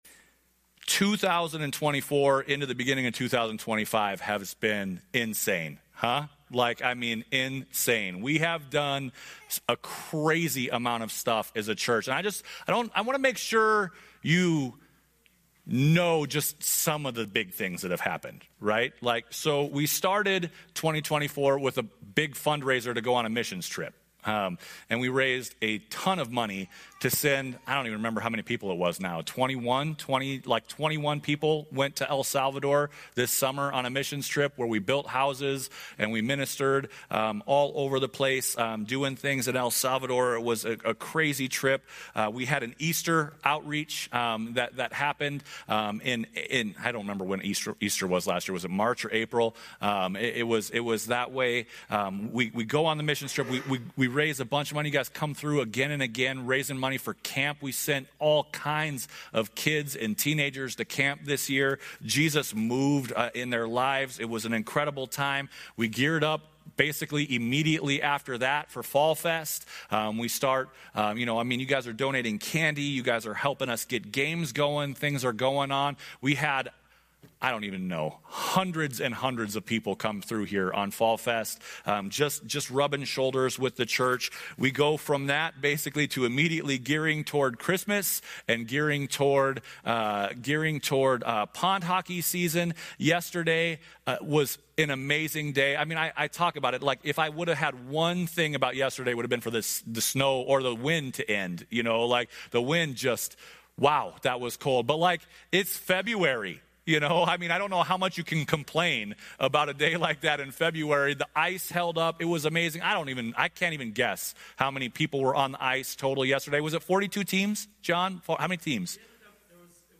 Some sermons that aren't in a normal series